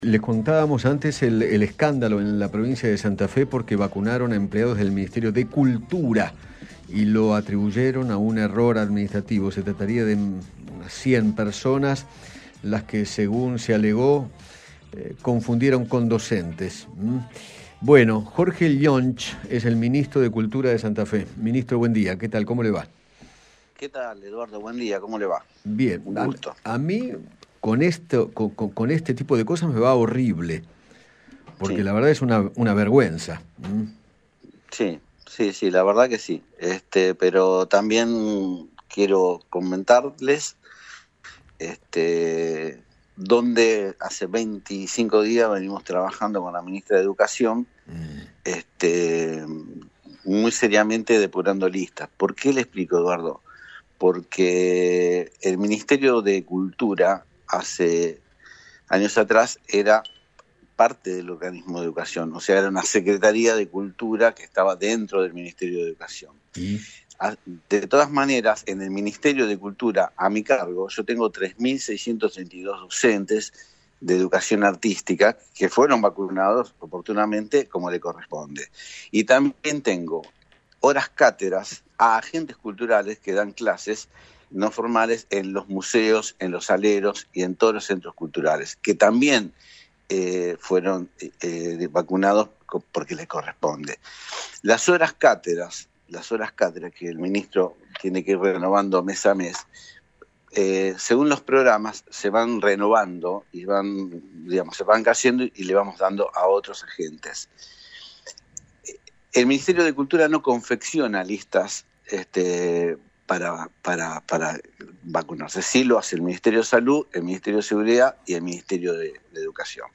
Jorge Llonch, ministro de Cultura de Santa Fe, dialogó con Eduardo Feinmann sobre el supuesto “error administrativo” que se cometió al vacunar a empleados de su ministerio creyendo que eran docentes.